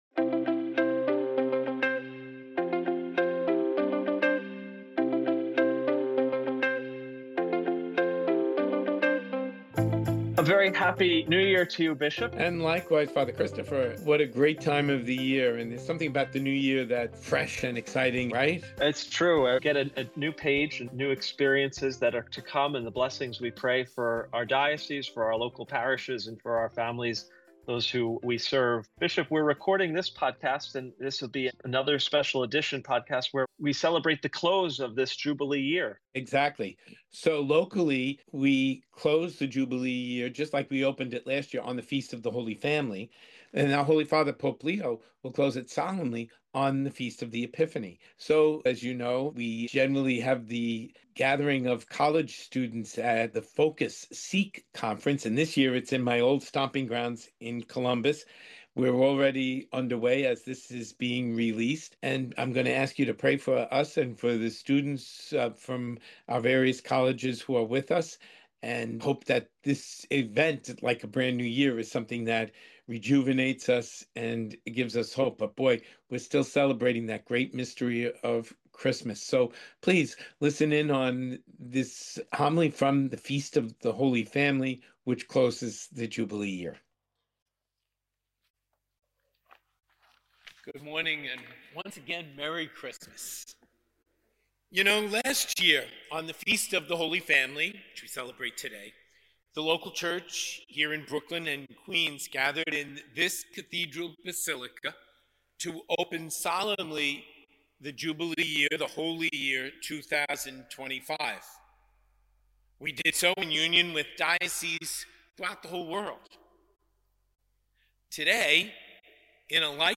In this episode of Big City Catholics, we hear Bishop Brennan’s homily for the Feast of the Holy Family, which marks the close of the Jubilee year for the local church. He reflects on Joseph and Mary as the first pilgrims of hope, fleeing Herod’s persecution while carrying the Christ child and facing life’s realities with strength from God.